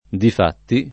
vai all'elenco alfabetico delle voci ingrandisci il carattere 100% rimpicciolisci il carattere stampa invia tramite posta elettronica codividi su Facebook difatti [ dif # tti ] (meno com. di fatti [id.]) avv. — non diffatti